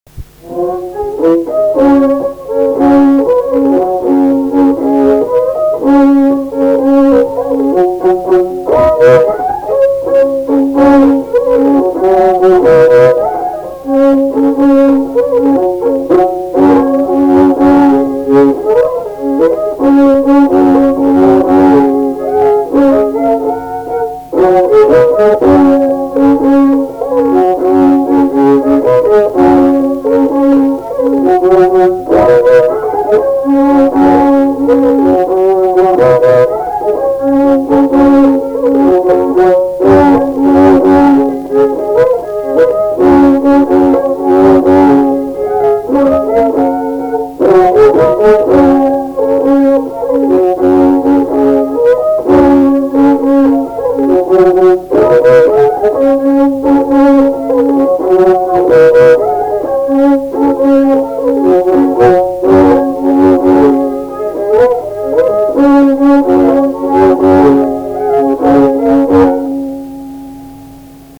Krakoviakas
šokis